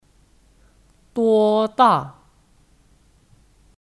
多大 (Duōdà 多大)